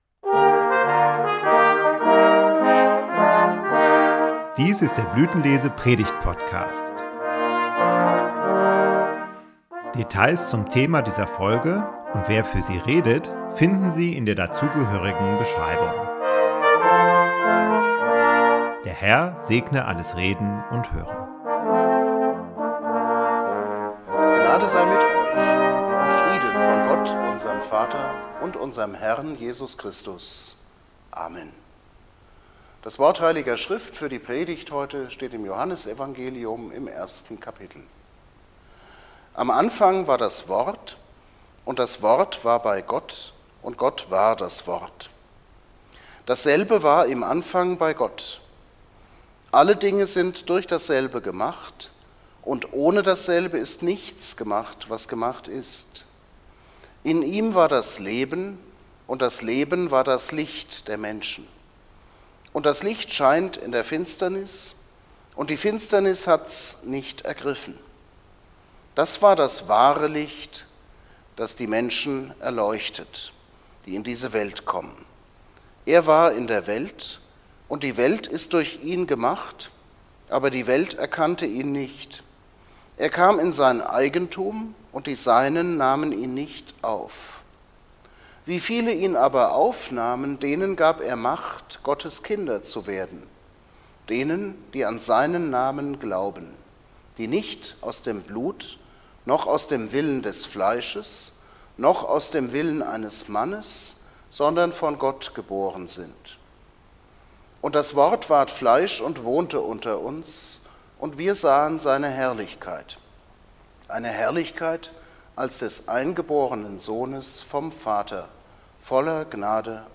Blütenlese Predigt-Podcast